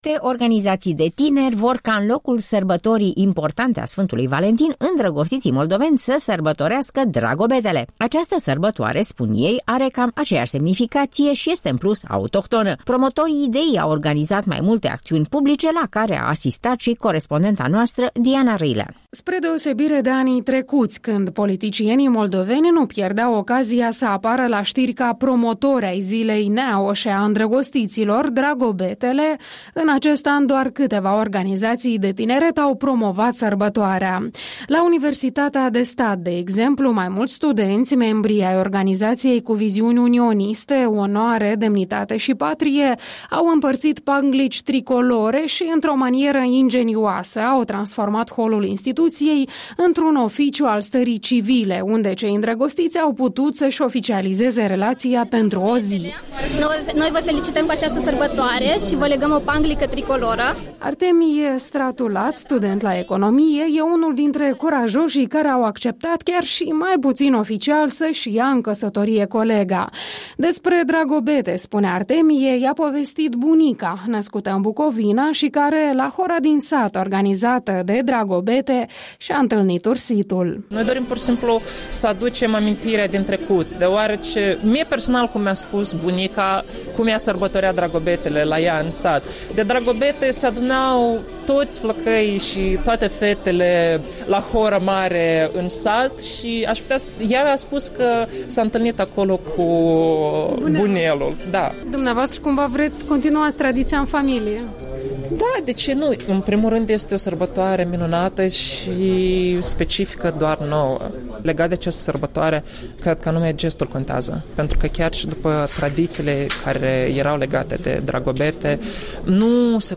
Un reportaj cu ocazia sărbătorii Dragobetelui.